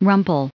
2032_rumple.ogg